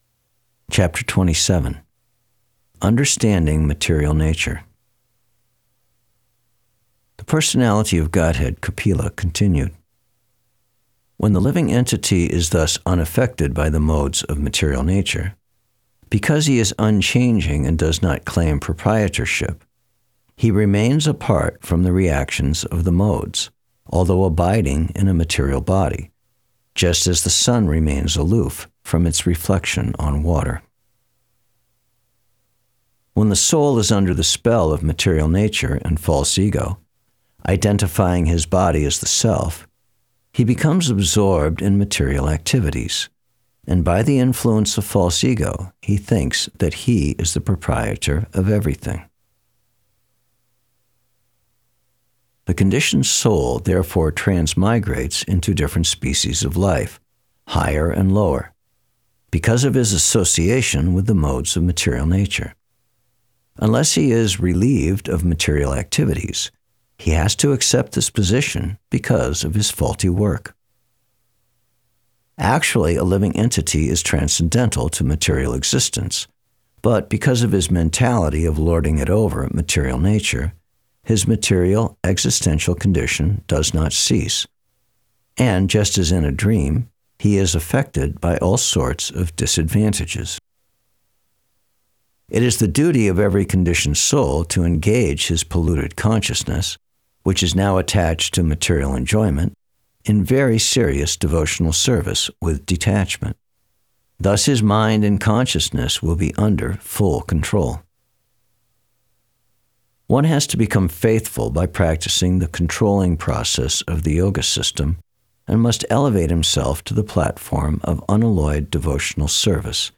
Thank you very much for this clear reading =) Really appreciate it.
Ch_27_SB_3rd_Canto_Verses_Only.mp3